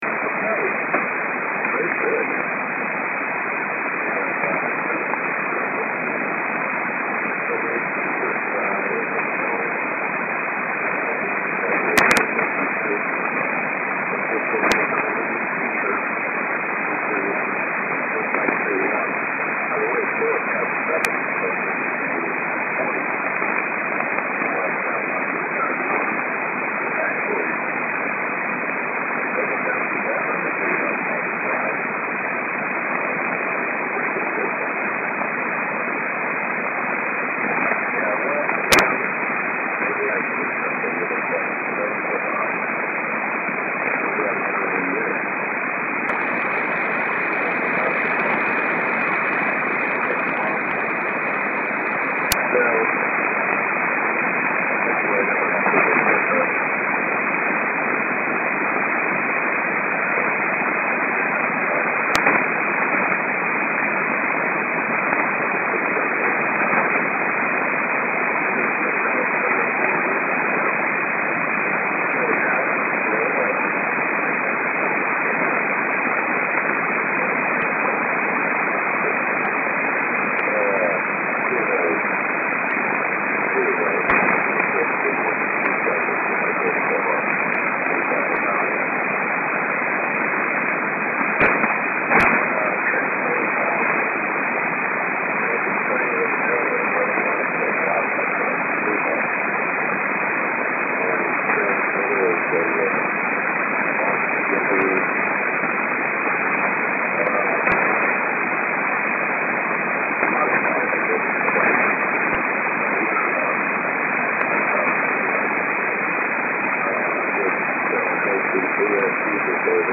A site mainly dedicated to Mediumwave Dxing
US pirate heard in the past on SW